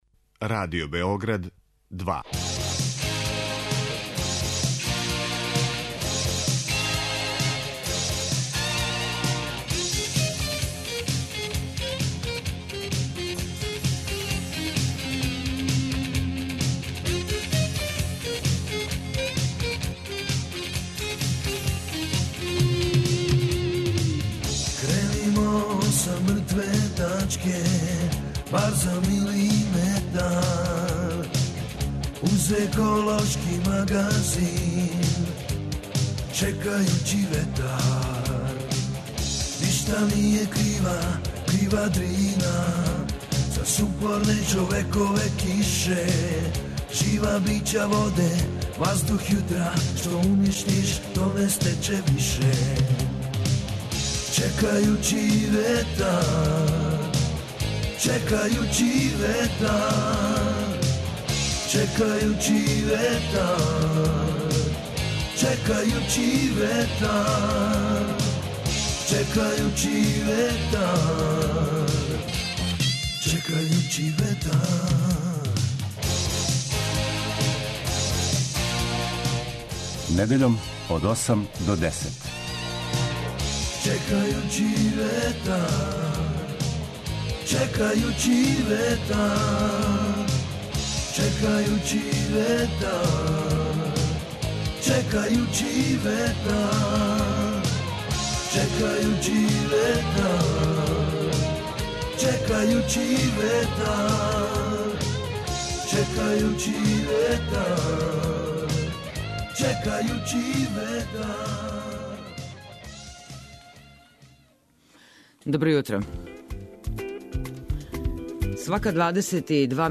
У нишком студију